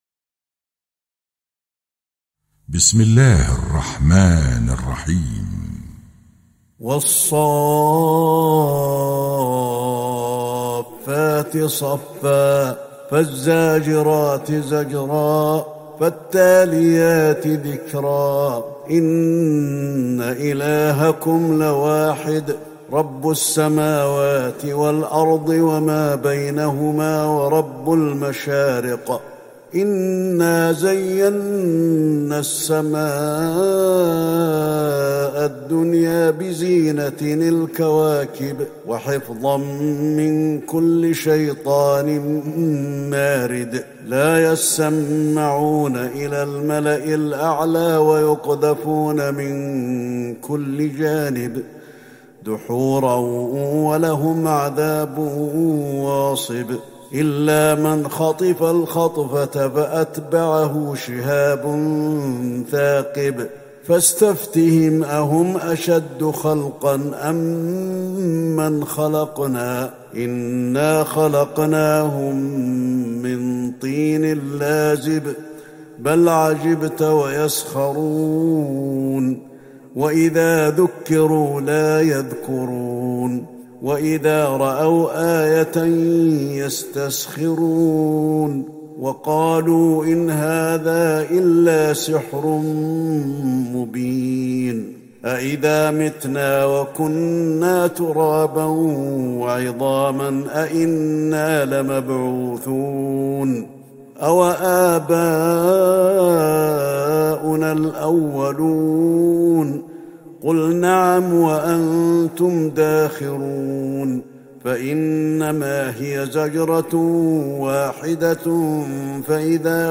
تهجد ٢٥ رمضان ١٤٤١هـ من سورة الصافات { ١-١١٣ } > تراويح الحرم النبوي عام 1441 🕌 > التراويح - تلاوات الحرمين